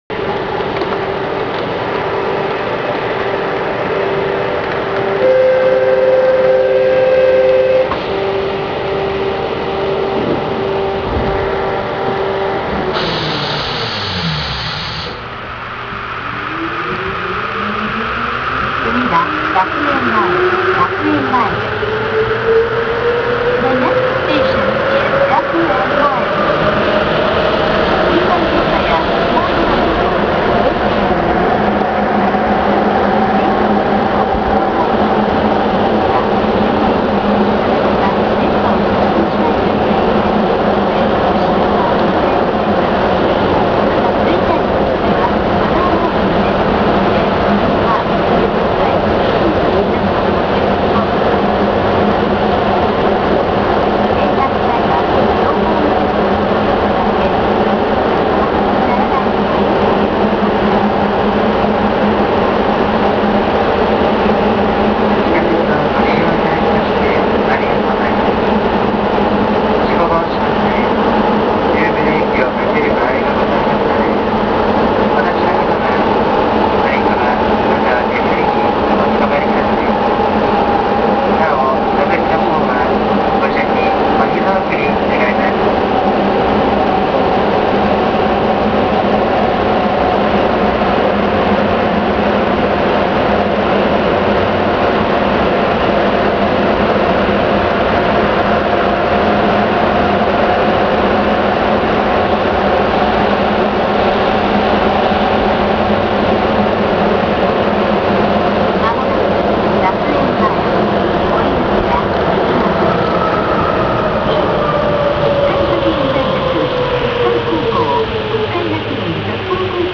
・7000形走行音
【東豊線】豊平公園〜学園前（2分15秒：734KB）…低音が目立たない車両
発車時の低音がよく響く車両とそうではない車両がいるようです。